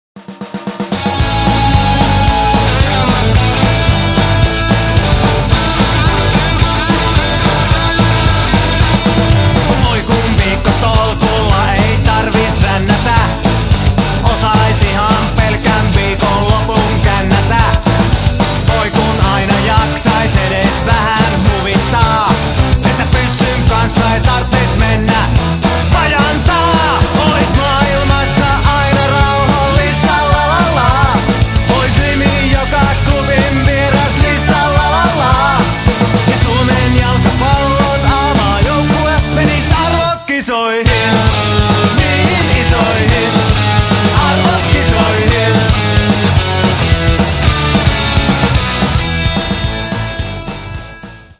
(Compressed/Mono 402kb)   Download!